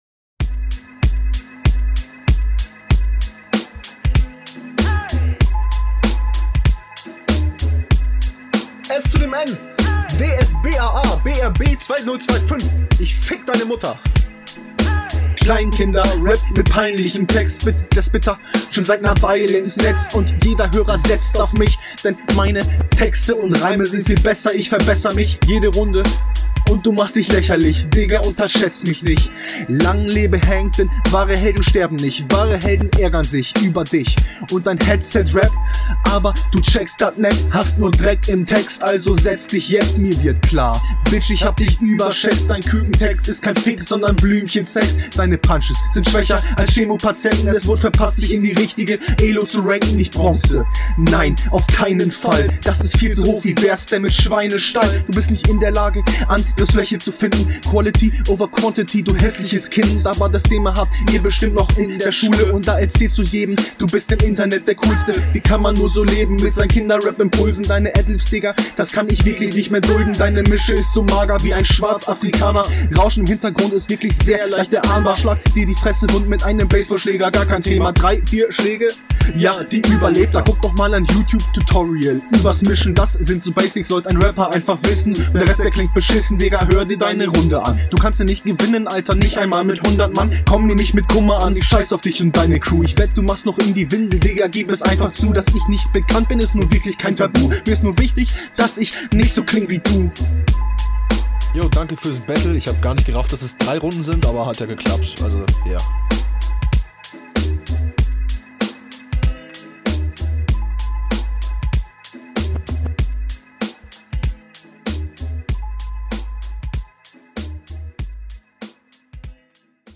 Simpler Flow, aber der sitzt dafür.